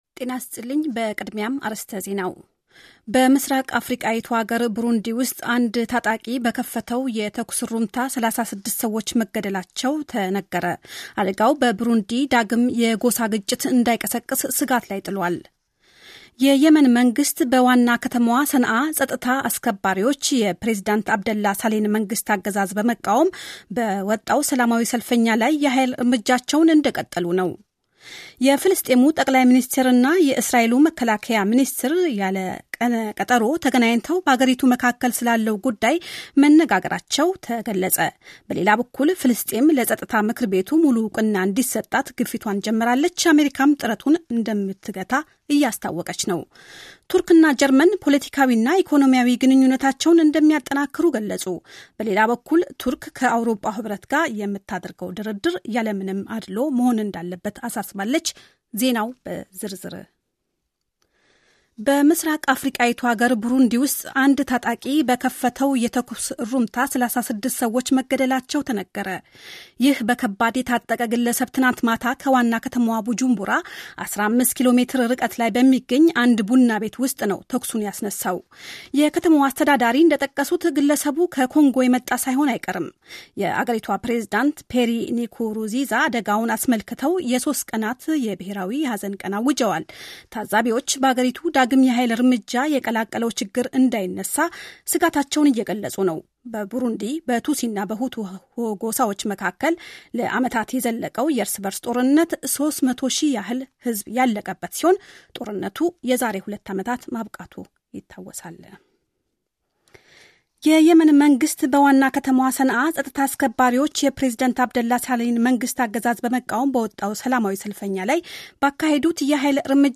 ጀርመን ራዲዮ ዜናዎች – Sep 19, 2011